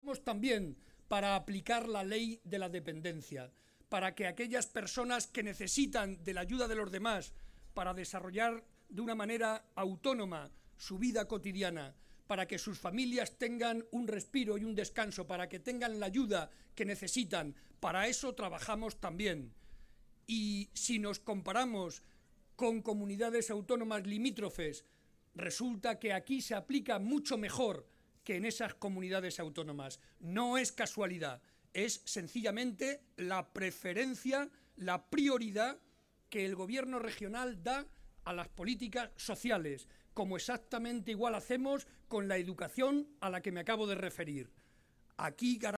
El secretario regional del PSOE y presidente de C-LM, José María Barreda, asistió a la presentación de la plataforma “Vecino a Vecino”
Cortes de audio de la rueda de prensa